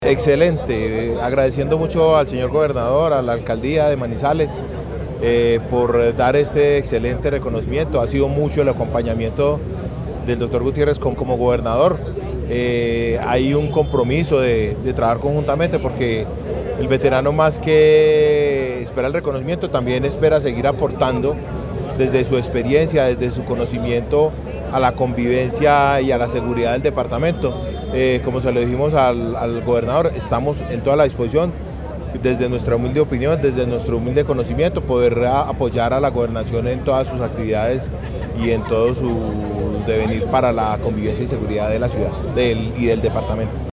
veterano.